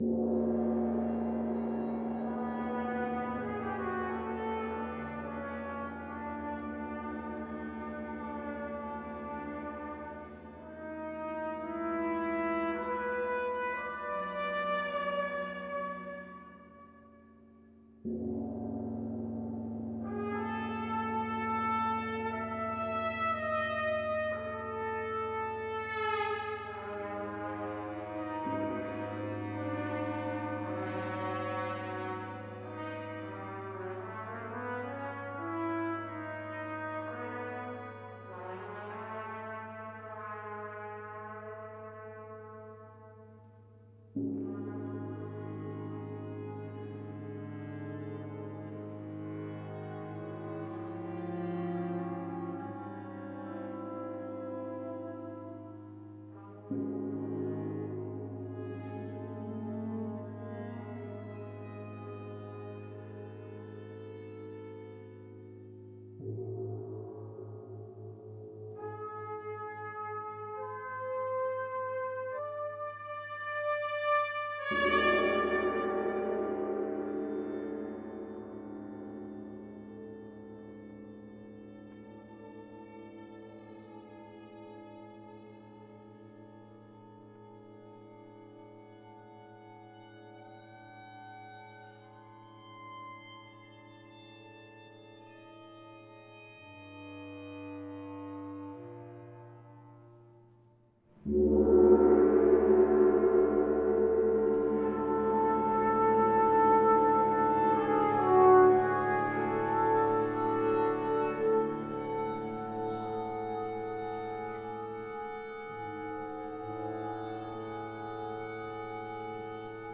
KONZERT FÜR 50 WINDGONGS
und kleines Ensemble
Die relativ dünnen Tamtams reagieren auf das Ensemble, indem sie wie Hallplatten in alten Tonstudios “mitsingen”.
Im Unterschied zu gewöhnlichen Tamtams haben sie einen wärmeren Klang, einen etwas deutlicher hervortretenden Grundton, bei leisem Anschlag fast wie ein Gong, sie sprechen schnell an, und bieten eine Vielfalt von Klängen, je nach Dynamik, Punkt und Rhythmus des Anschlags, bzw. Länge des Wirbels.
Die Windgongs werden auch direkt gespielt, mehr oder weniger traditionell, mit weichen und harten Schlägeln, Bögen und anderen Materialien.